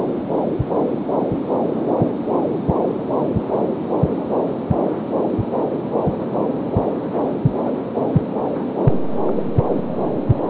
Click Here to hear her heart beat!